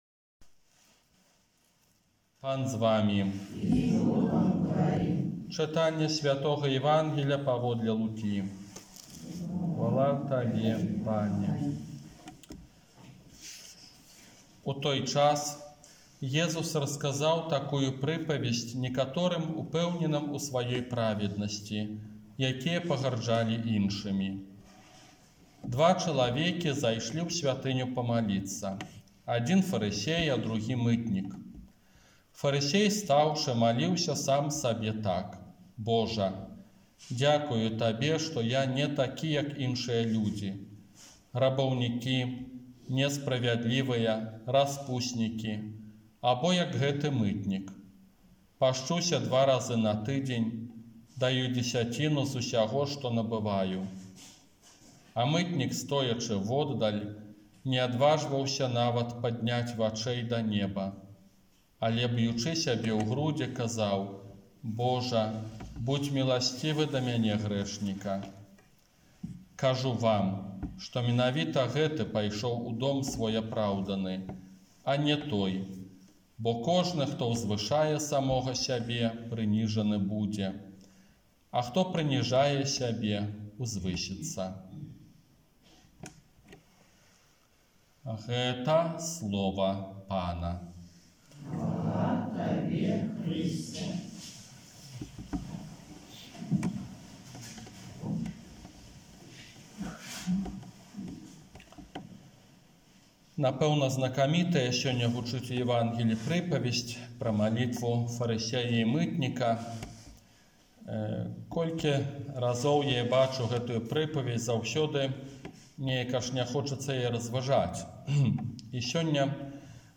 Казанне на трыццатую звычайную нядзелю